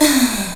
VOX SHORTS-1 0014.wav